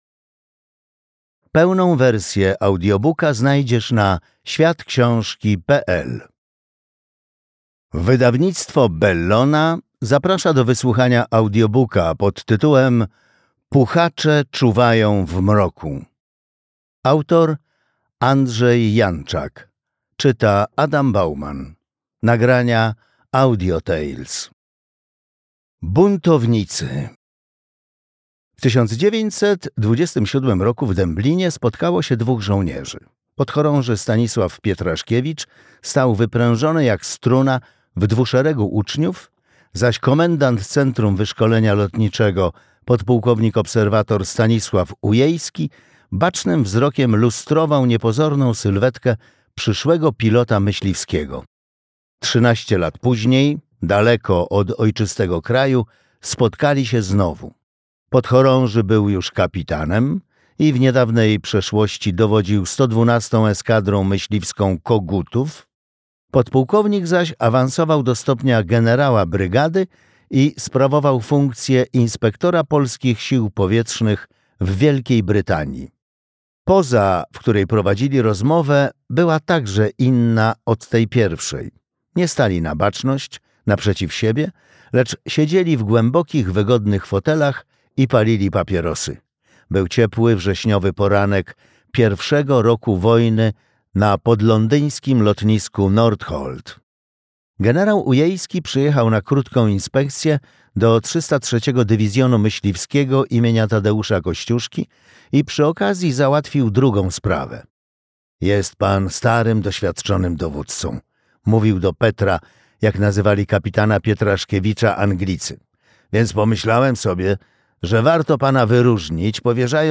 Puchacze czuwają w mroku - Andrzej R. Janczak - audiobook